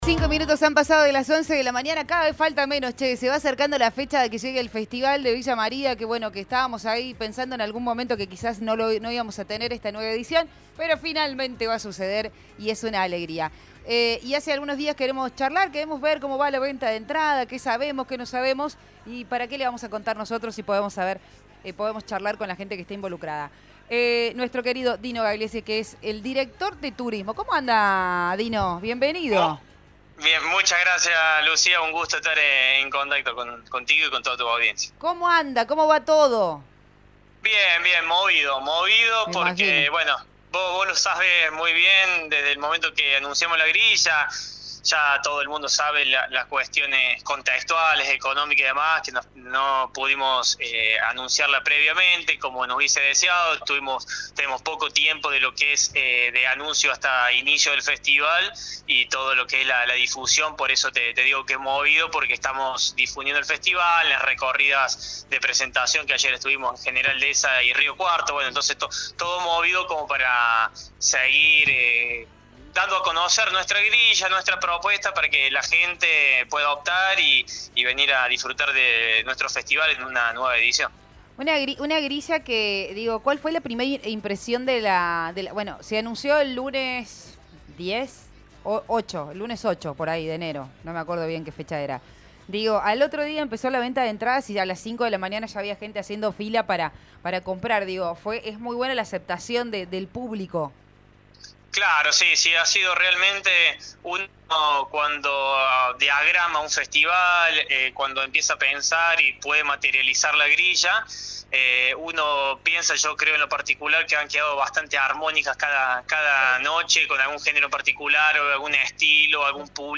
En la recta final de los preparativos para el 56º Festival de Peñas, el director de Turismo, Dino Gagliese, conversó con nosotros para brindar detalles sobre el evento que tiene a Villa María vibrando de expectativas.